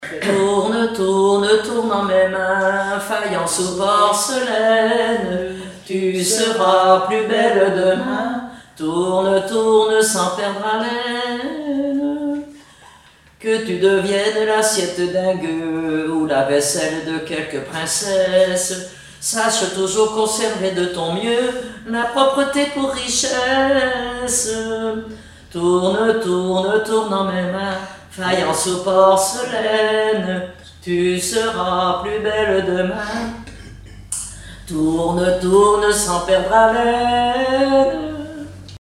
Genre strophique
Chansons et formulettes enfantines
Pièce musicale inédite